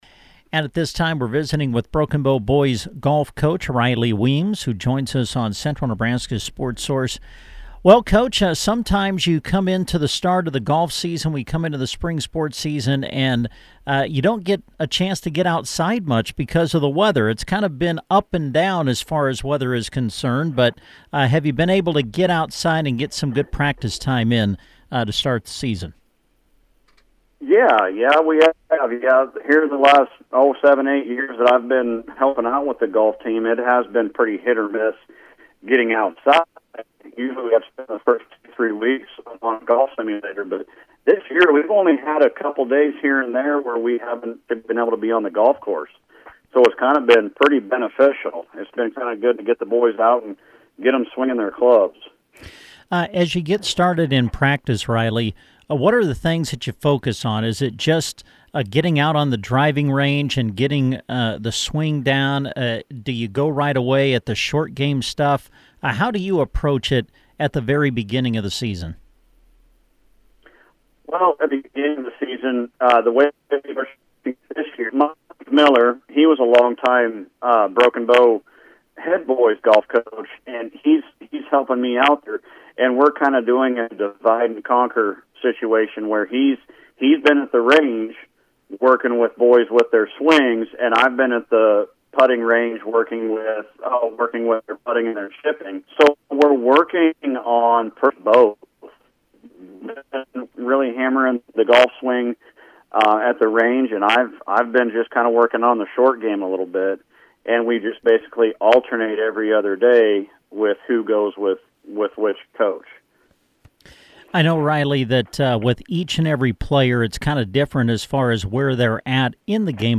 BOW-GOLF-INTERVIEW_.mp3